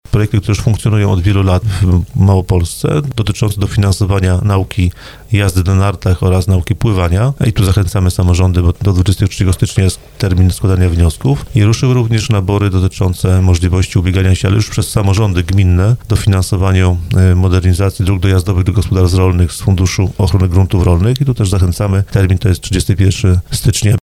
mówi Wiceprzewodniczący Sejmiku Województwa Małopolskiego Wojciech Skruch.